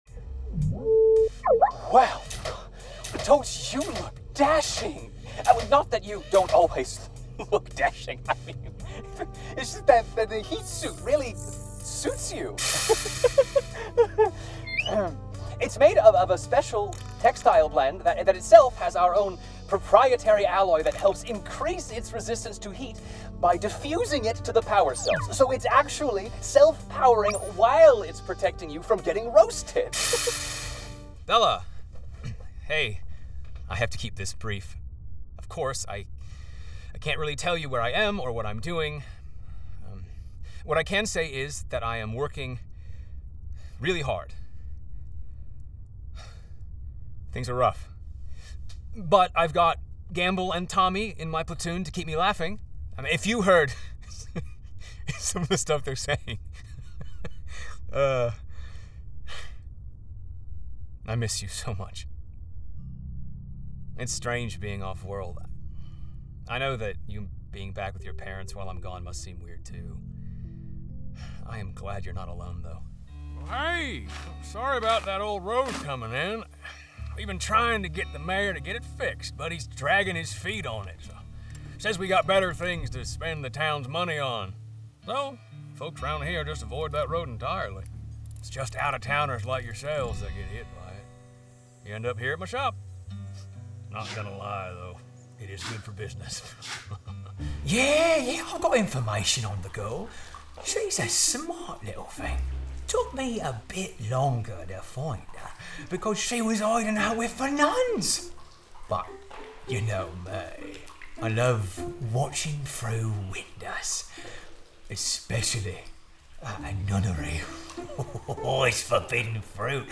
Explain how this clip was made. I work in a professional home studio, and I have a travel kit in case you need me to do a job while I’m on the road. Sennheiser MKH 416 Sound Proof Custom Built Booth